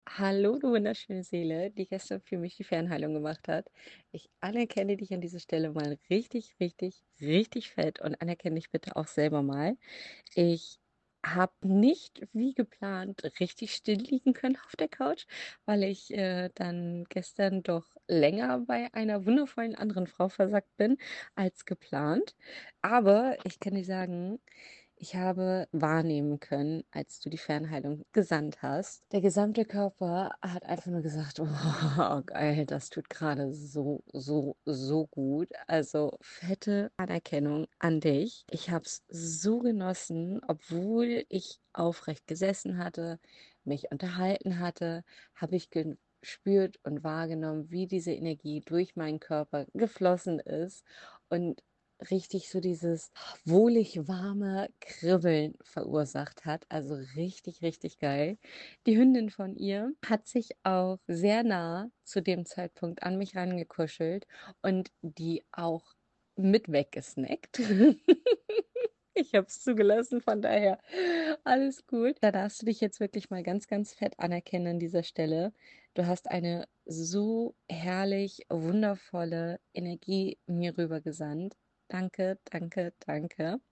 Erlebe wie tiefgreifend Veränderung sein kann – hier eine Seelenstimme als Feedback